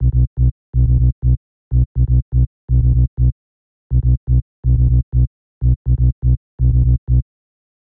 • tech house bass samples - B - 123.wav
tech_house_bass_samples_-_B_-_123_e6e.wav